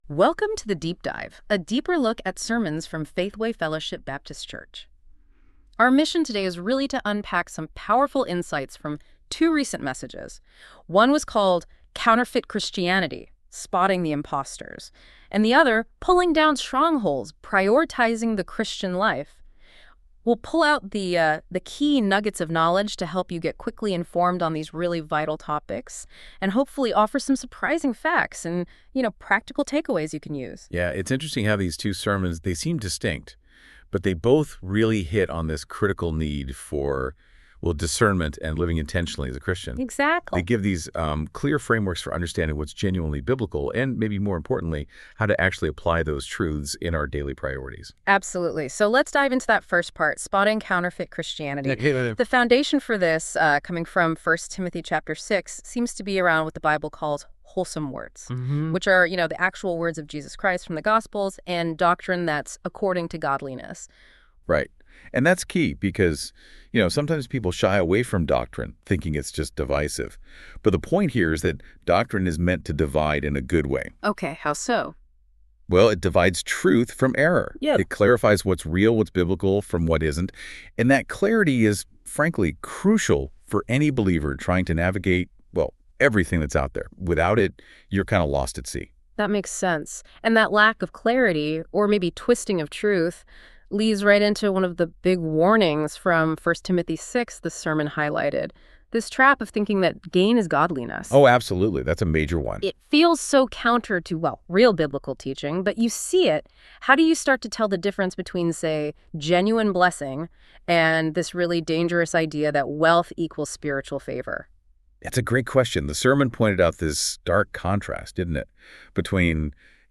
This content is AI generated for fun.